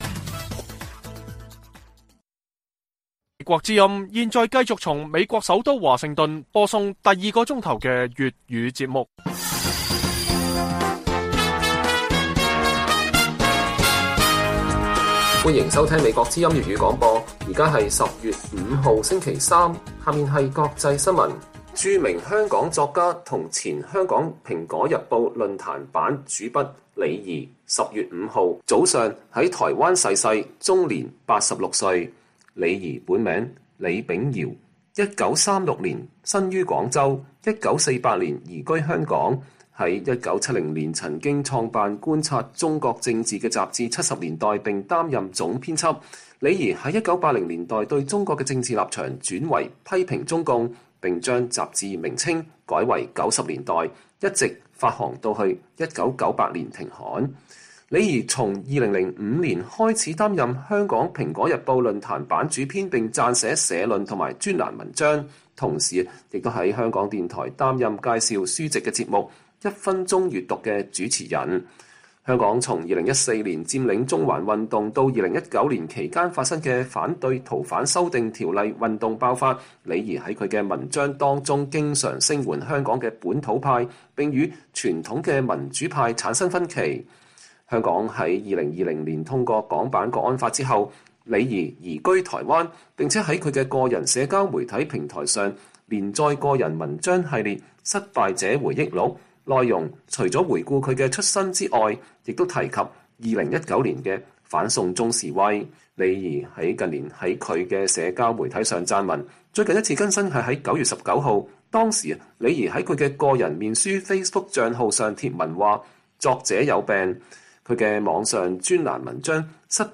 粵語新聞 晚上10-11點: 烏克蘭和西方國家強烈譴責普京正式吞併烏克蘭領土